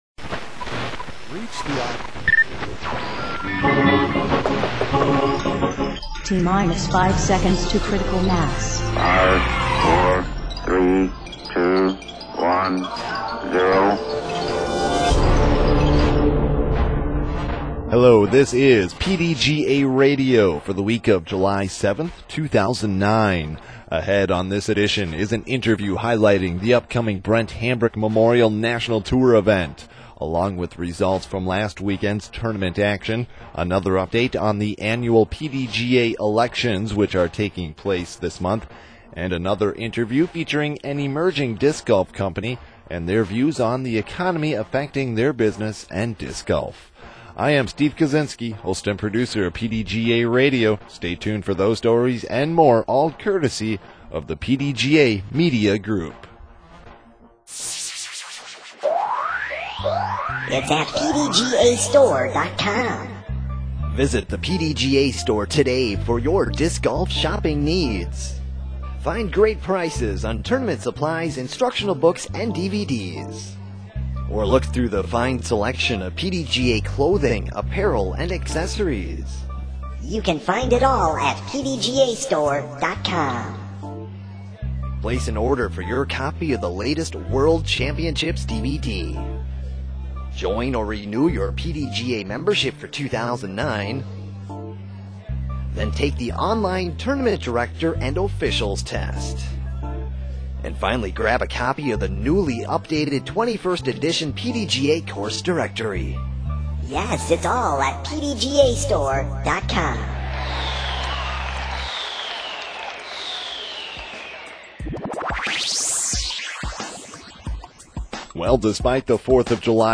PDGA Radio Program: 7/7/09